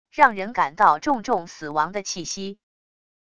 让人感到重重死亡的气息wav音频